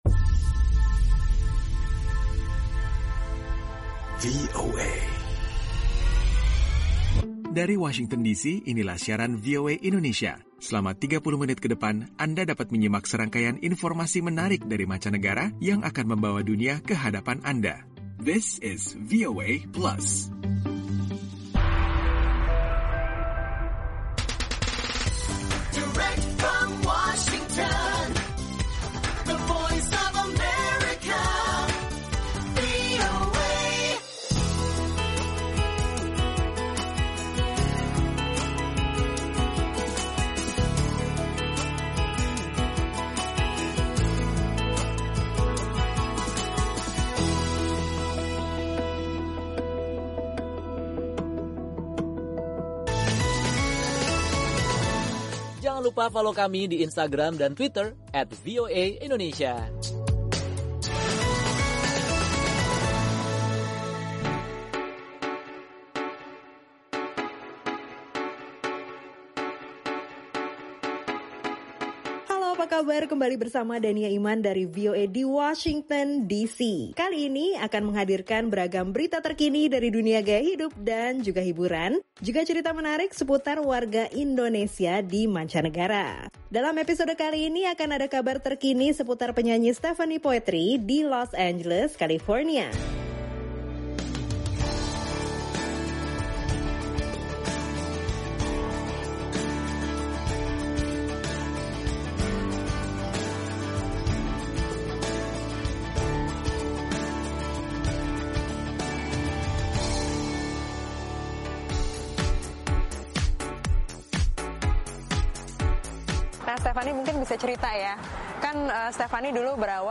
VOA Plus: Obrolan Bersama Penyanyi Stephanie Poetri Seputar Karir Dalam Dunia Musik.